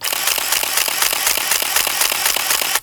• repetitive short shutter burst SLR camera.wav
repetitive_short_shutter_burst_SLR_camera_4Da.wav